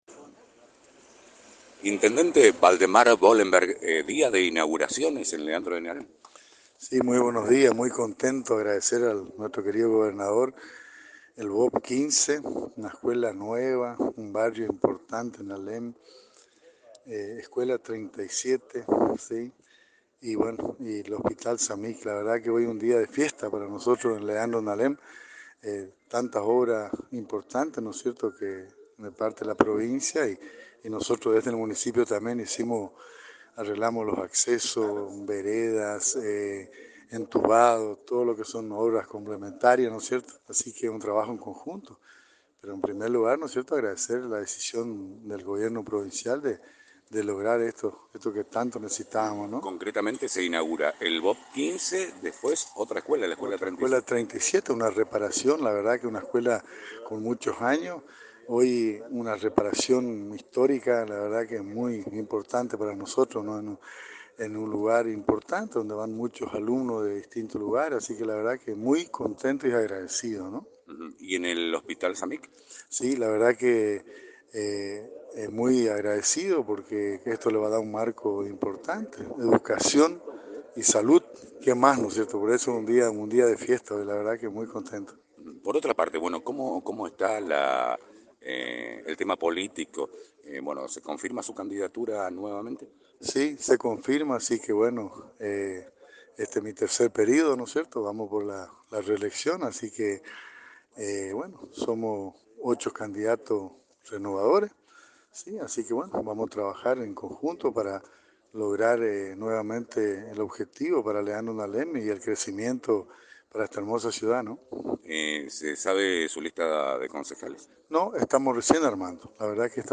En declaraciones a este medio, el intendente Waldemar ‘Valdy’ Wolenberg, confirmó que buscará seguir al frente de la comuna en las próximas elecciones del 7 de mayo.